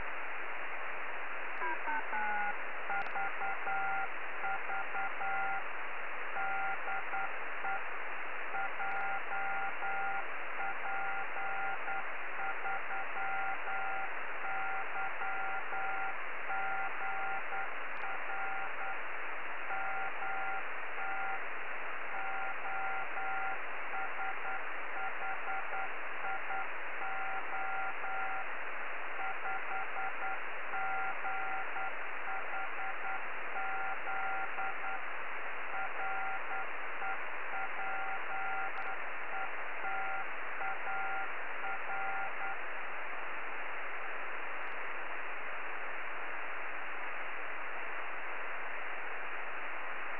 京都−豊橋165Km　鈴鹿山系越えの見通し外伝播　　　　受信モードはCW
RS気味の信号 信号強く明瞭
信号強度は常に変化しています。ゆっくりしたQSBといったところ。